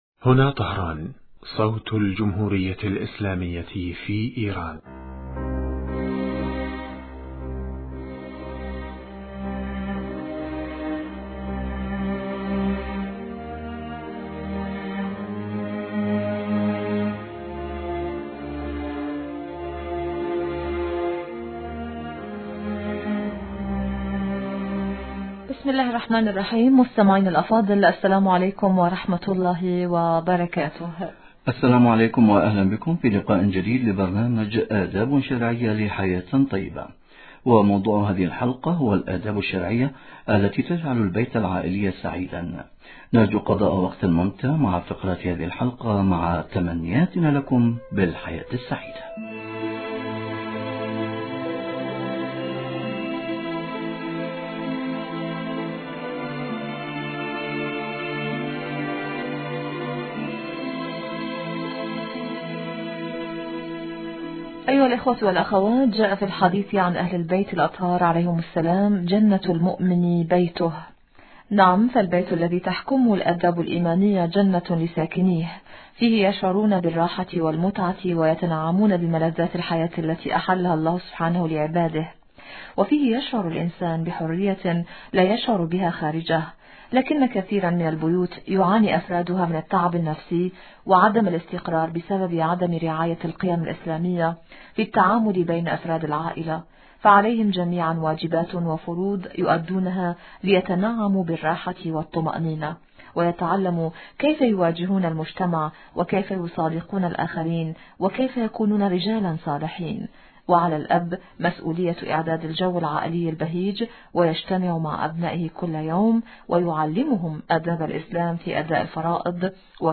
الباحث الإسلامي من لبنان